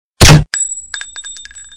Выстрел с глушителем (звук для сообщения)